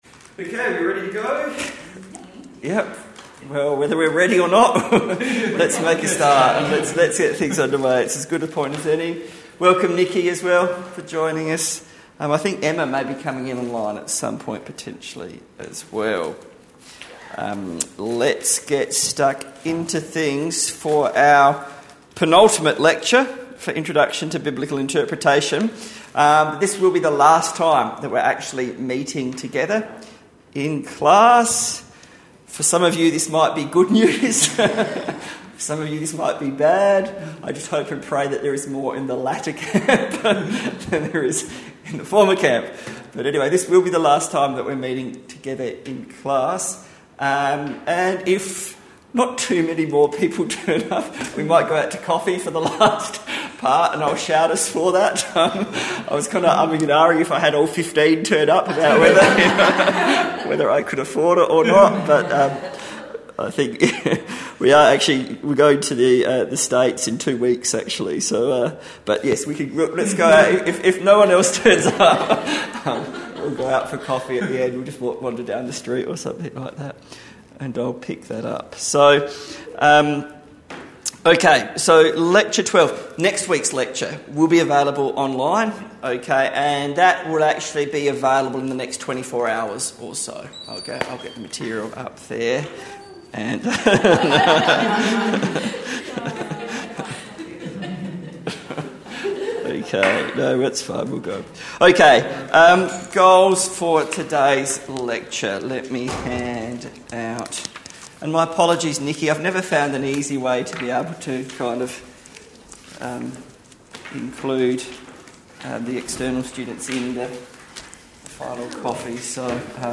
Lecture Audio 12 Lecture Audio 12